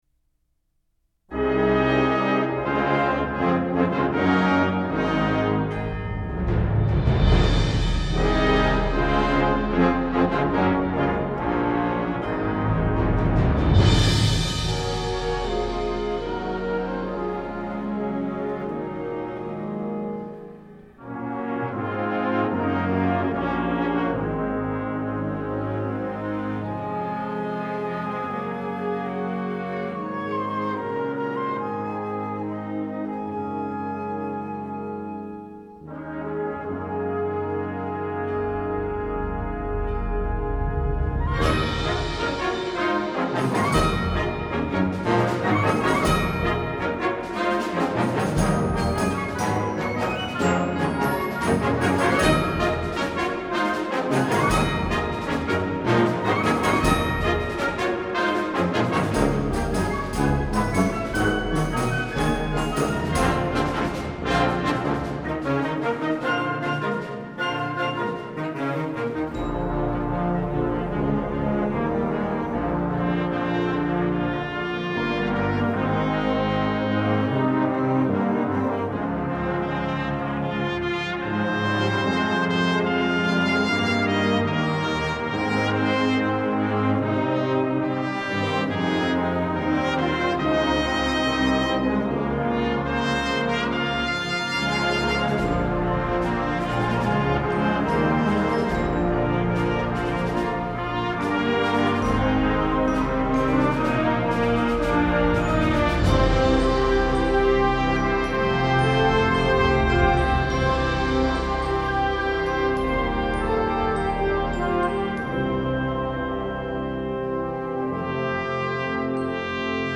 編成：吹奏楽
Piano
Timpani
Xylophone
Marimba
Vibraphone
[Percussion 2] Crash Cymbals, Snare Drum, Bass Drum